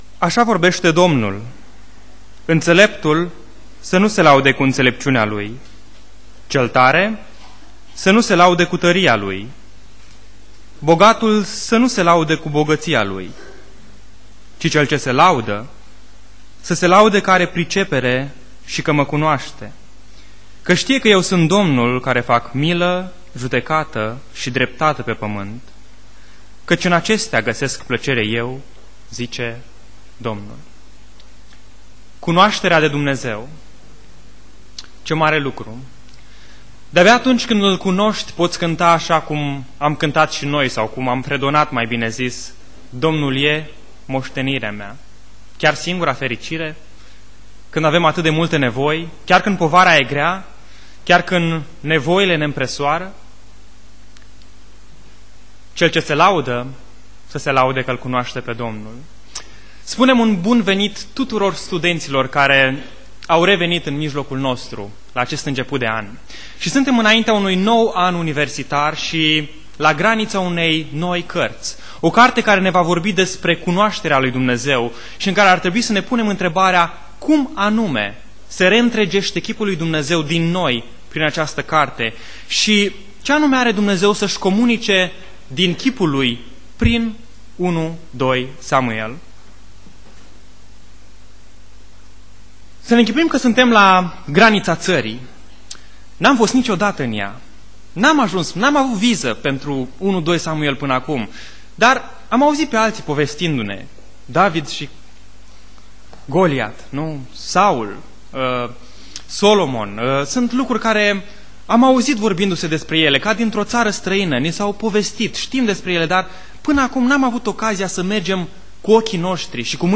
Predica 1 Samuel cap 1:1-2:11 Aplicatie